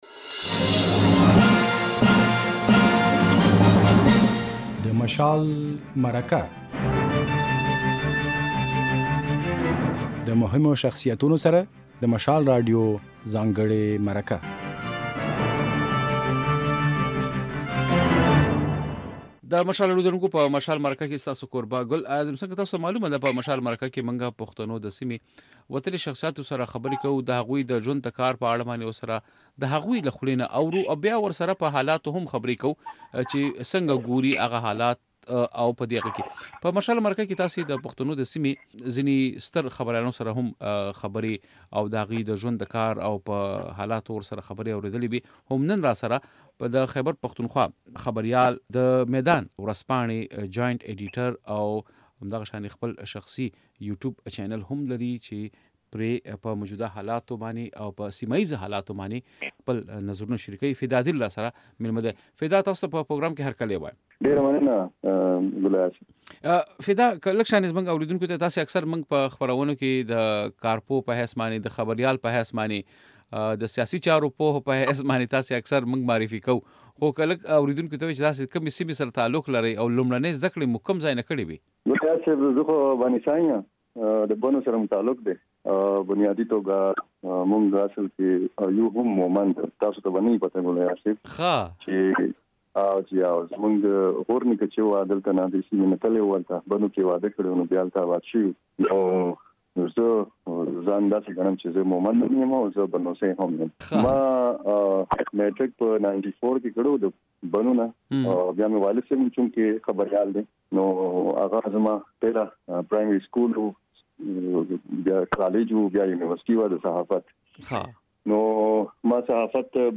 دی وايي، اوسمهال د خیبر پښتونخوا تر ټولو لویه مسله امنیت ده چې د هواري لپاره ورسره وفاقي حکومت باید پوره مرسته وکړي. بشپړه مرکه واورئ.